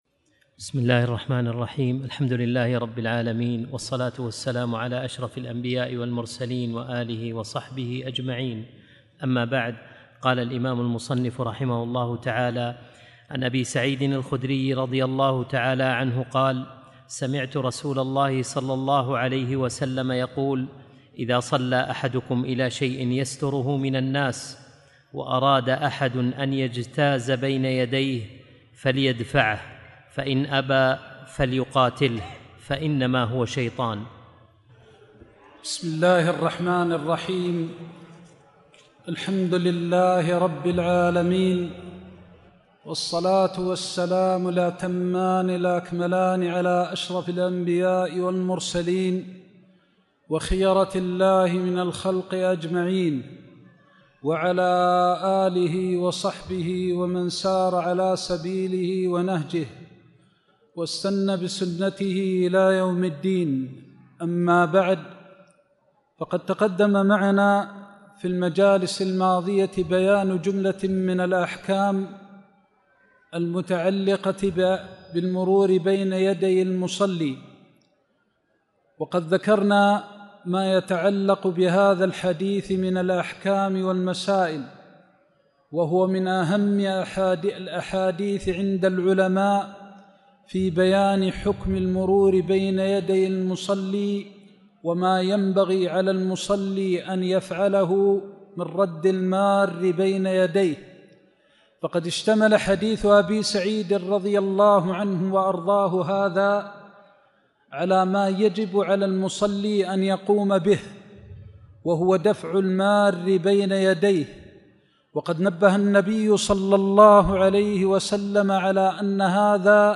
ارشيف دروس و فوائد الشيخ محمد المختار الشنقيطي